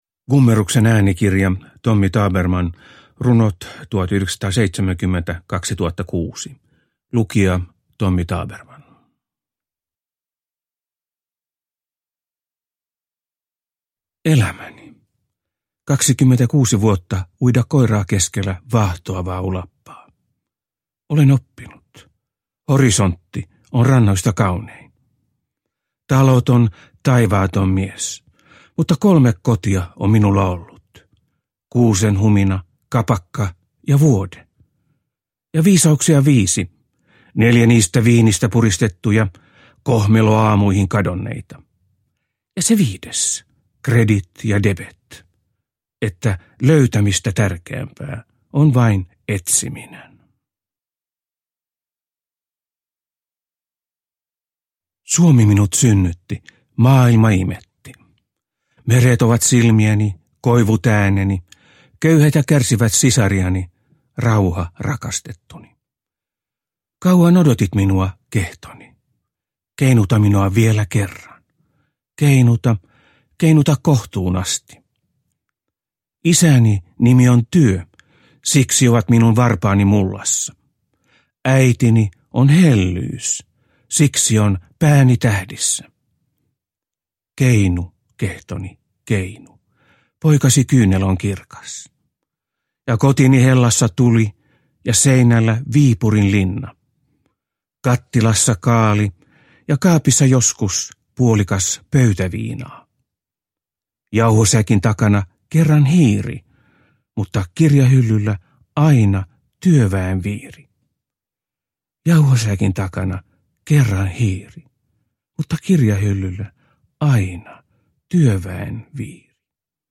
Rakastetun trubaduurin parhaat runot on julkaistu runoilijan itse lukemana äänikirjana.
Uppläsare: Tommy Tabermann